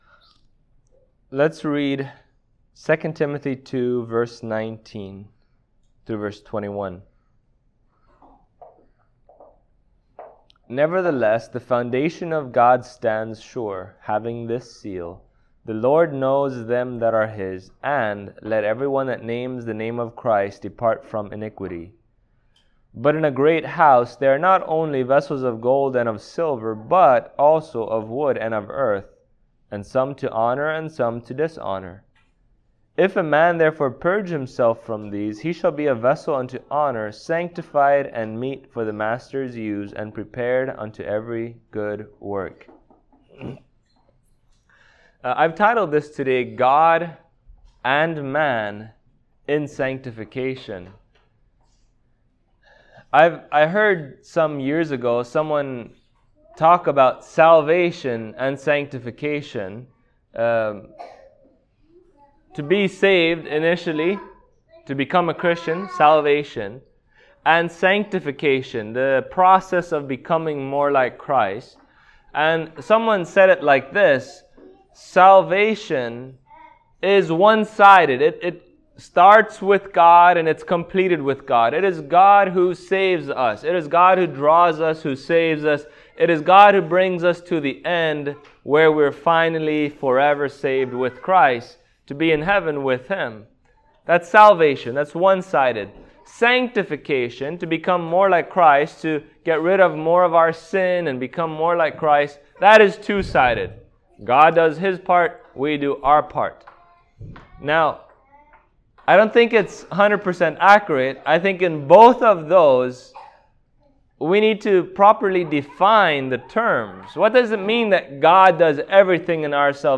Service Type: Sunday Morning Topics: Sanctification